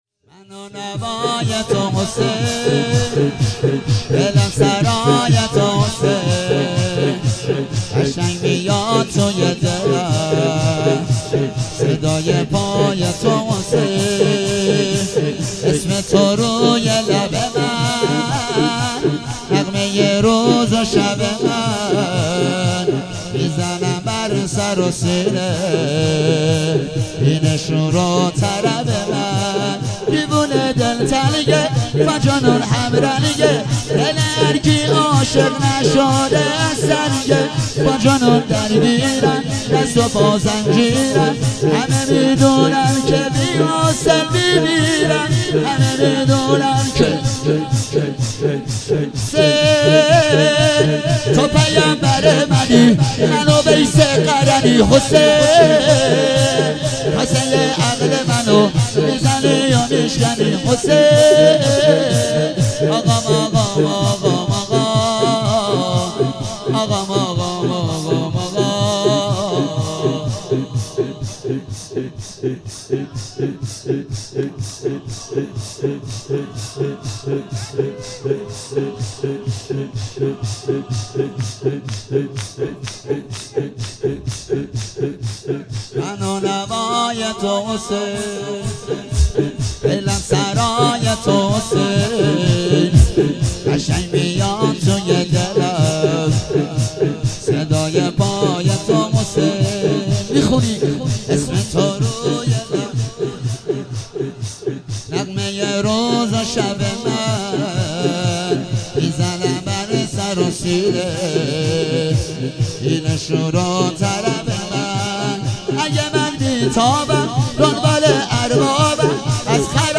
هفتگی 23 تیر - شور - منو نوای تو حسین